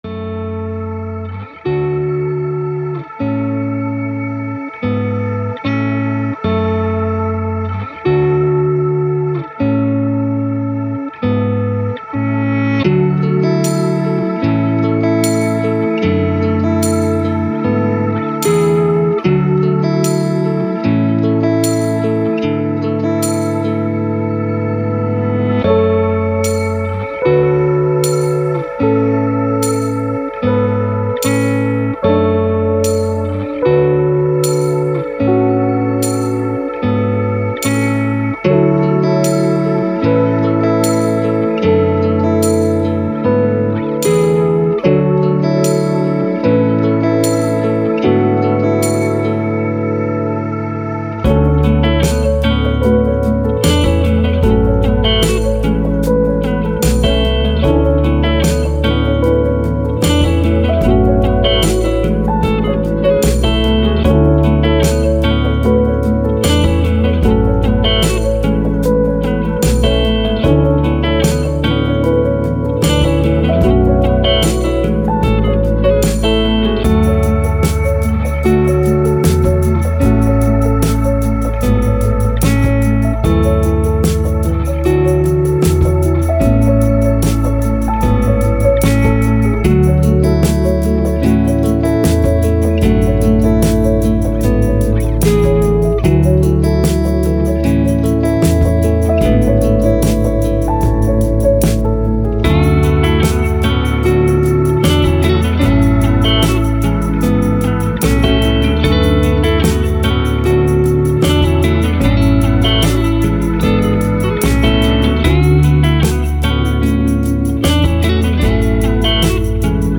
Ambient, Lofi, Chill, Downtempo, Thoughtful